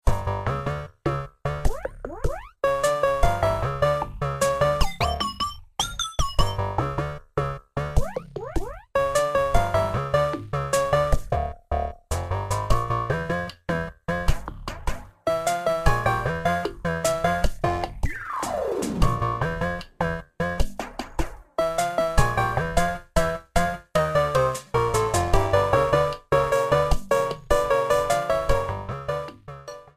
Clipped to 30 seconds and applied fade-out.